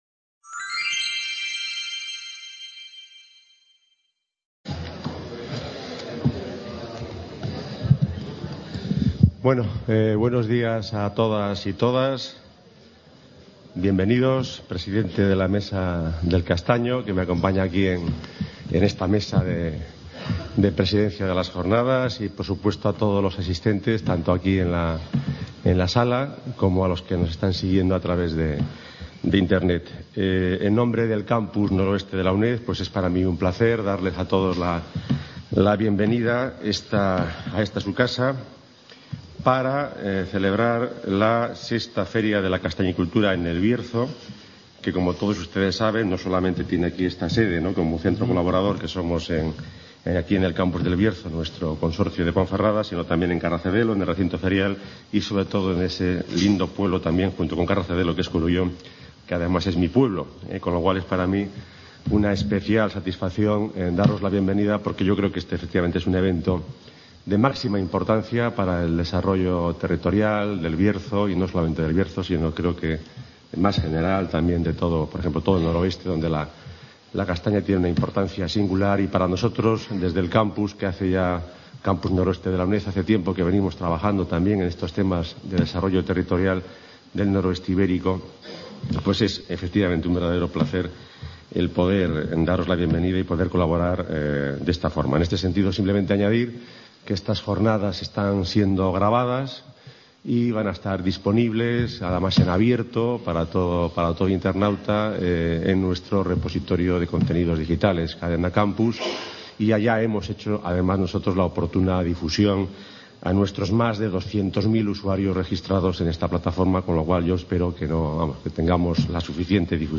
Apertura JORNADAS TÉCNICAS BIOCASTANEA 2015
C.A. Ponferrada - Biocastanea 2015 - VI Edición Feria de la Castañicultura en el Bierzo - I Monográfico sobre productos derivados de la castaña, el castaño y su hábitat